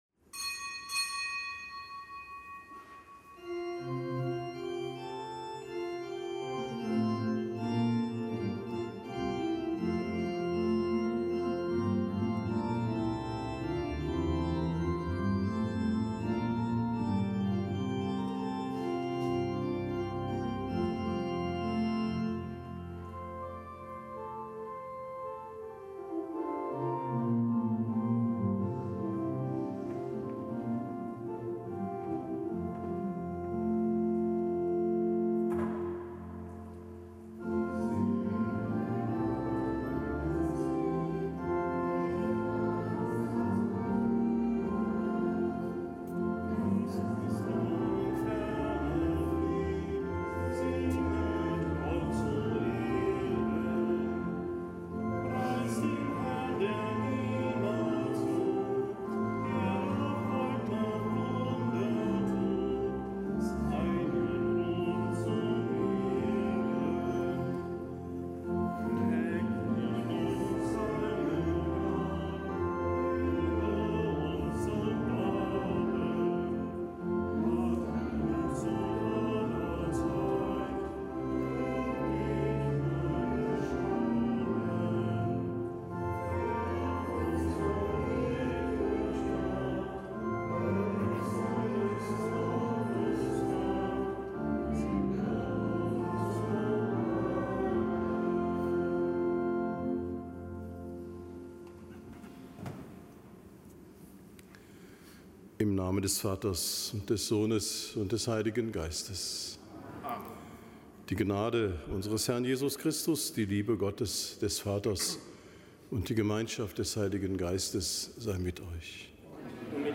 Kapitelsmesse am Freitag der fünfundzwanzigsten Woche im Jahreskreis
Kapitelsmesse aus dem Kölner Dom am Freitag der fünfundzwanzigsten Woche im Jahreskreis, nichtgebotener Gedenktag des Heiligen Kosmas und des Heiligen Damian, Ärzte, Märtyrer.